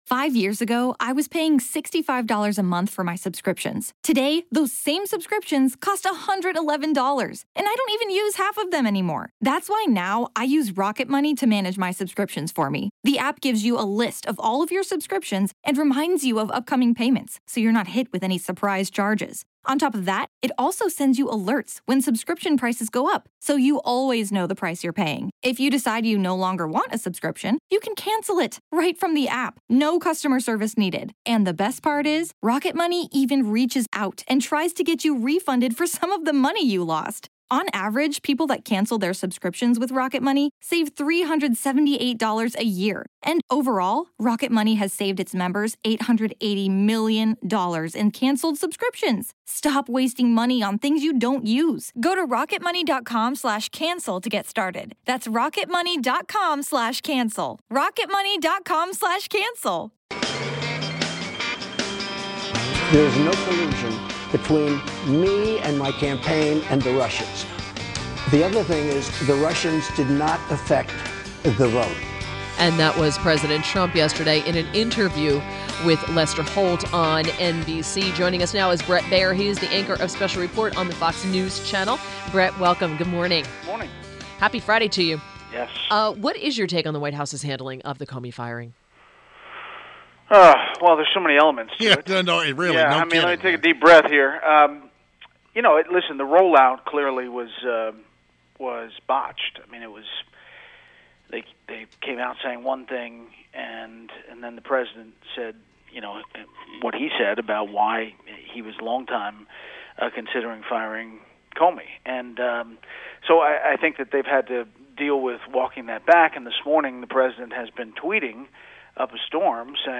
Interview – BRET BAIER – Anchor of Special Report on Fox News Channel, weekdays at 6 pm – discussed the fallout of Comey’s firing.